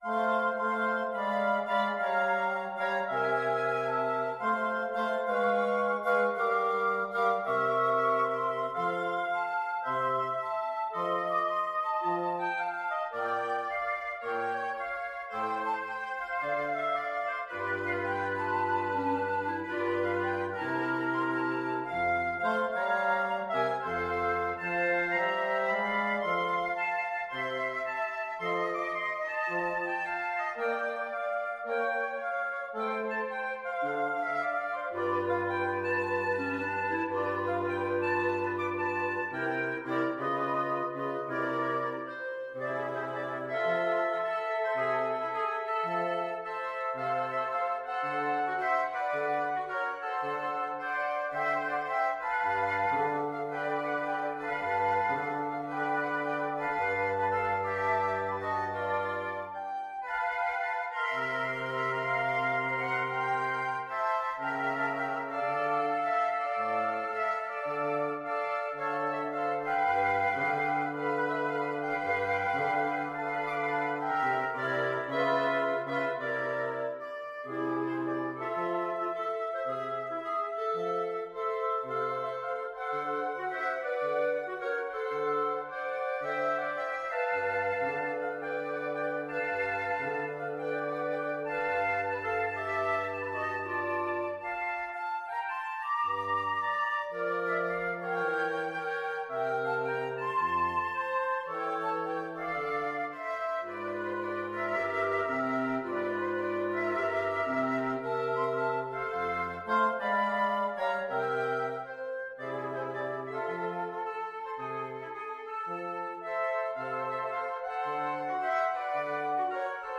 FluteOboeClarinetBassoon
2/2 (View more 2/2 Music)
~ = 110 Moderate swing
Pop (View more Pop Wind Quartet Music)